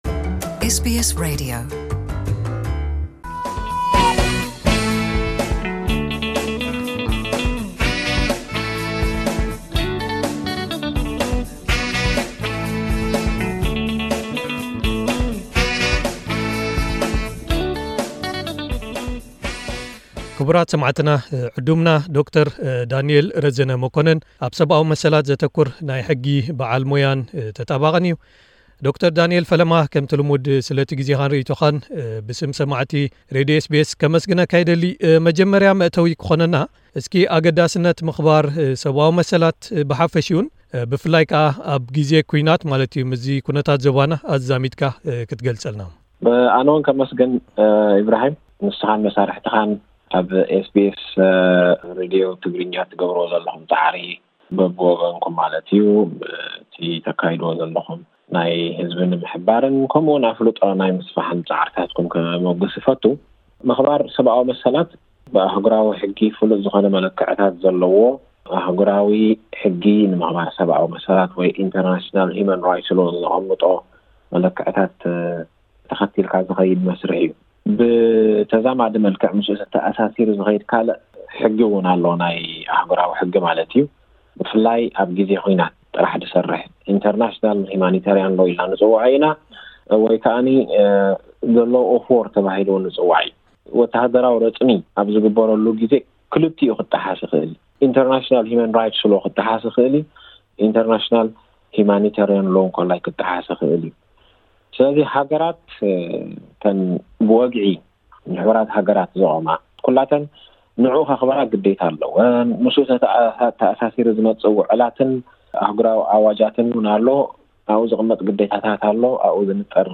ኣብ'ቲ ኣብ ትግራይ ዝተፈጥረ ኩነታትን ሰብኣዊ ቅልውላዋትን ኣየኖት ሕግታት'ዮም ተጣሒሶም ዘለው? ተሓታትነት ክሳብ ዝለዓለ ናብ መን'ዩ ክበጽሕ? ቃለ መሕትት